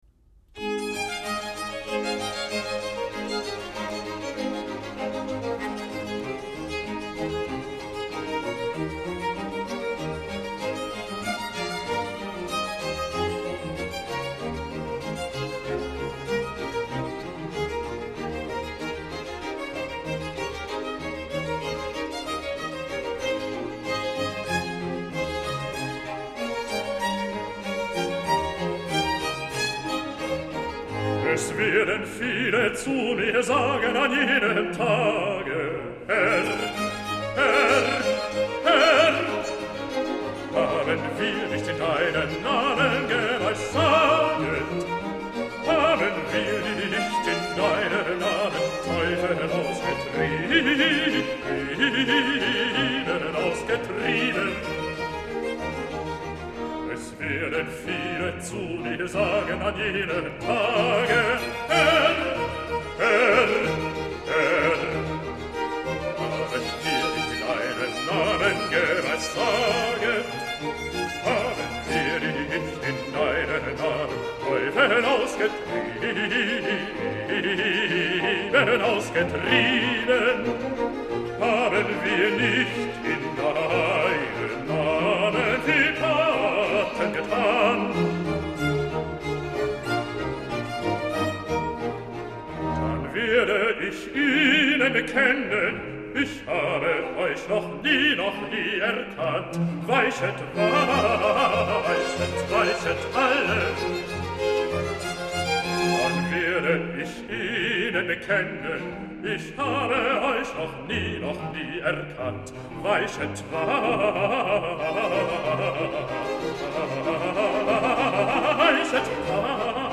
4. Seconde partie – Arioso de basse
Violons I/II, Violes, Continuo